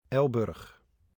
Elburg (Dutch pronunciation: [ˈɛlbʏr(ə)x]